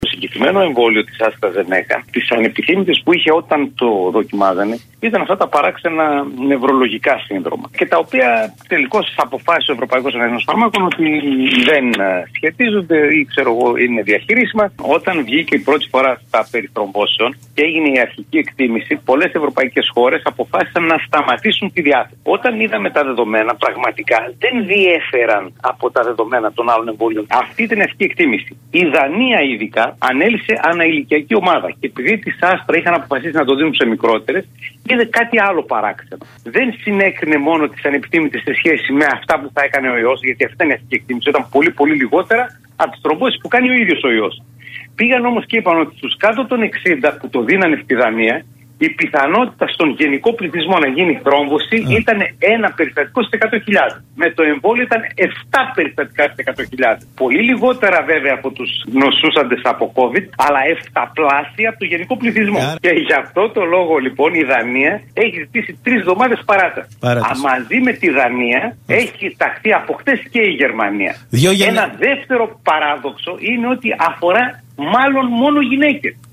μιλώντας στο realfm 97,8